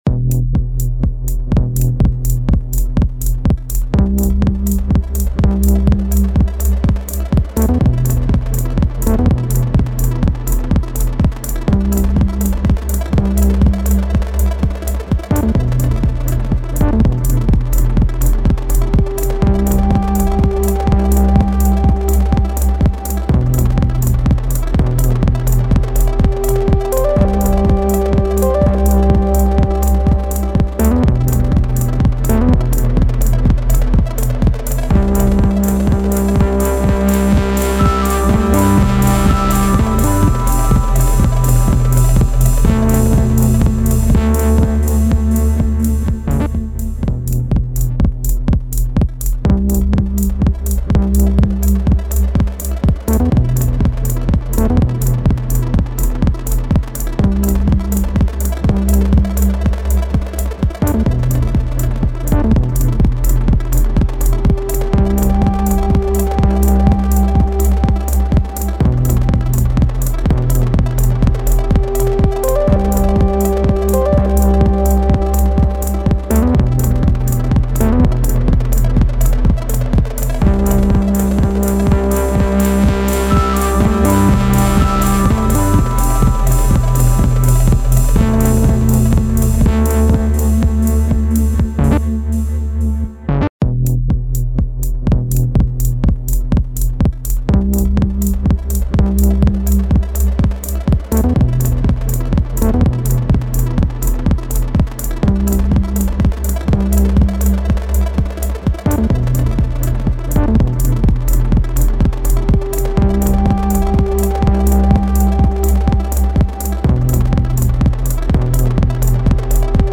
Musikalische Kreationen (Remixe)